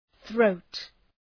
Προφορά
{ɵrəʋt}